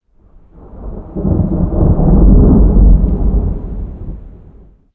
thunder14.ogg